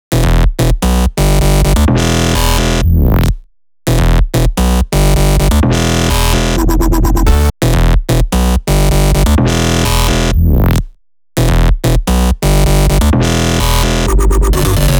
VDE 128BPM Renegade Melody 2 Root E.wav